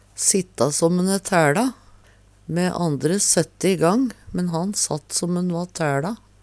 sitta som`n æ tæLa - Numedalsmål (en-US)